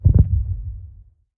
heartbeat_2.ogg